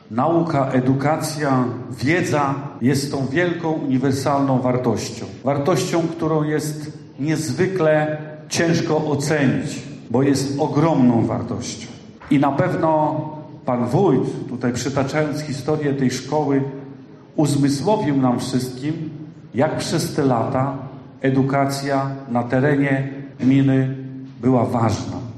Tak Szkoła Podstawowa w Goworowie obchodziła wczoraj swój złoty jubileusz 50-lecia nadania imienia Mikołaja Kopernika.
Edukacja jest niezwykle ważna podsumował radny Województwa Mazowieckiego, Mirosław Augustyniak: